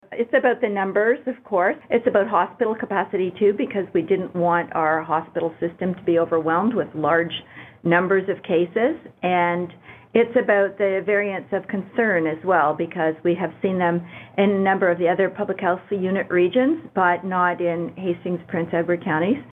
Christine Elliott spoke to Quinte News on Wednesday and says the decision to move our area back to the less-restrictive zone of the framework was based on a few factors.